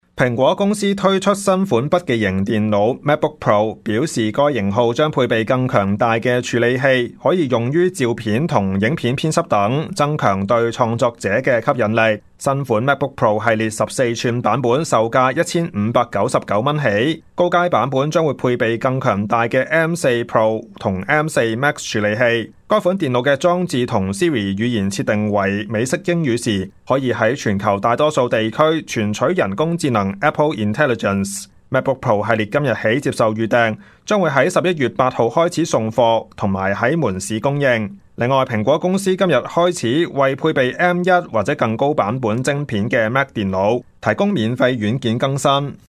news_clip_21097.mp3